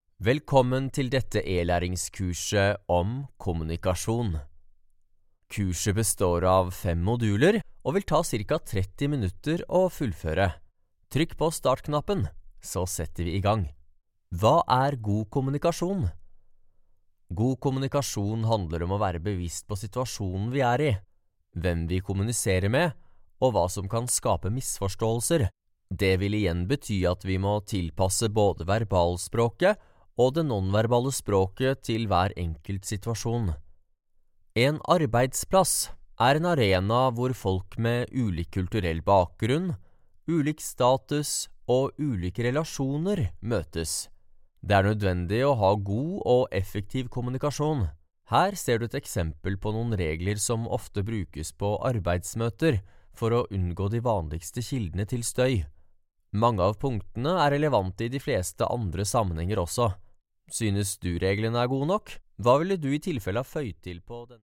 Male
Corporate, Energetic, Friendly, Warm, Young
My home studio gear delivers great sound quality.
My voice sounds relatively young. It has a friendly, crisp and trustworthy touch.
Microphone: Neuman TLM 103